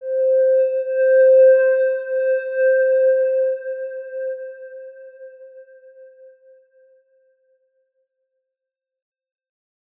X_Windwistle-C4-ff.wav